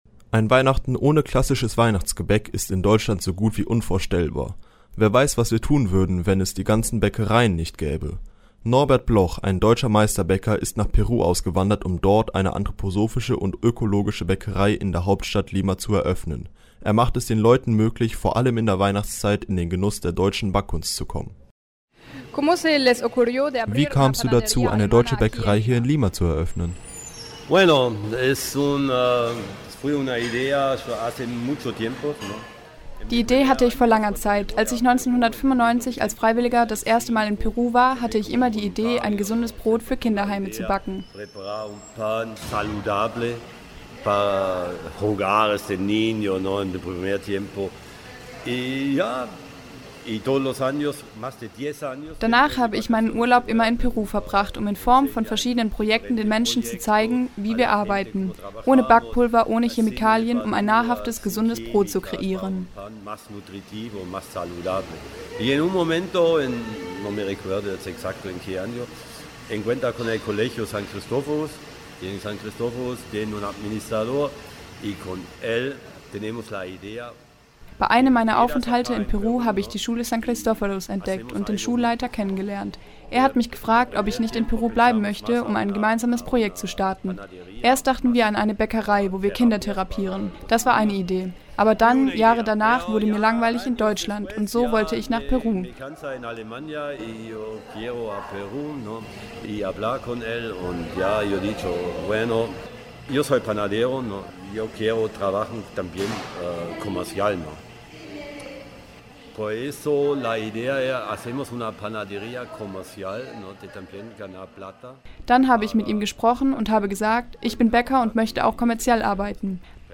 Antworten auf diese und viele weitere Fragen findet Ihr in unserem Interview.